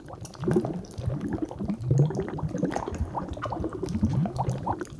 lp_slime.wav